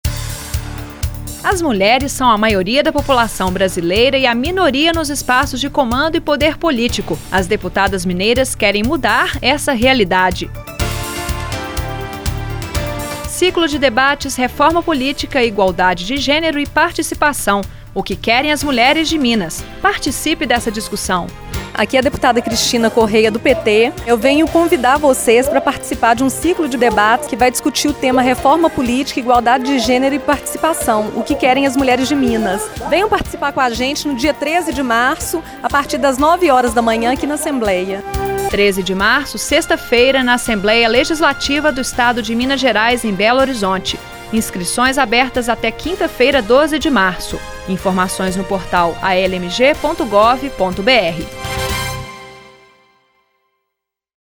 Ouça o convite da deputada Cristina Corrêa, PT